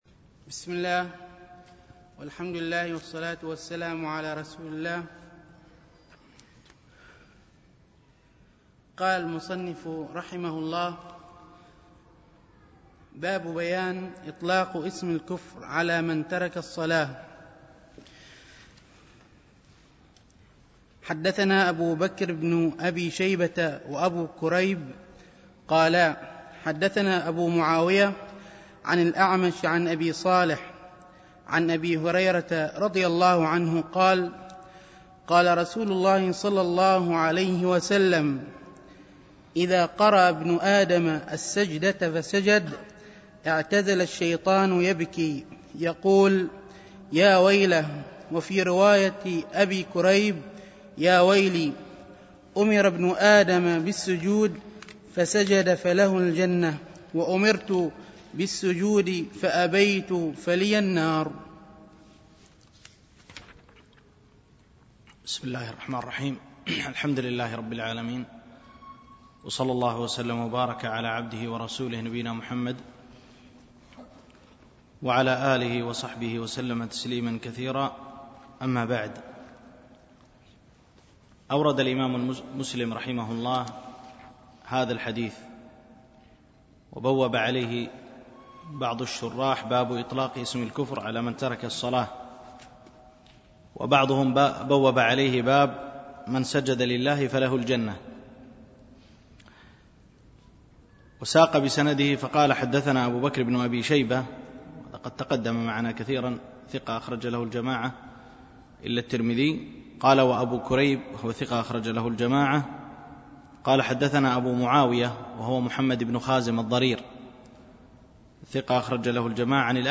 الألبوم: دروس مسجد عائشة (برعاية مركز رياض الصالحين ـ بدبي)